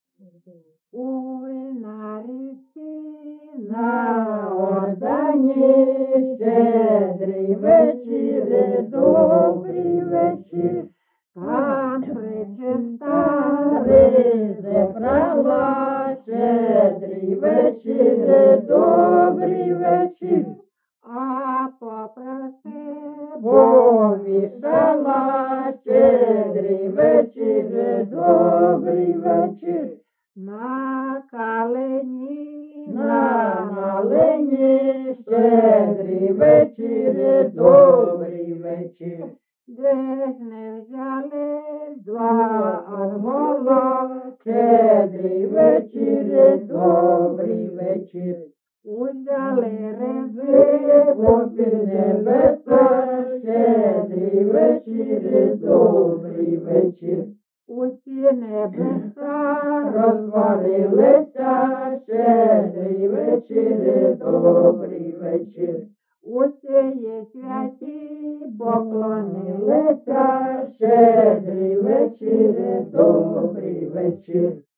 GenreNew Year Carol (Shchedrivka)
Recording locationLyman, Zmiivskyi (Chuhuivskyi) District, Kharkiv obl., Ukraine, Sloboda Ukraine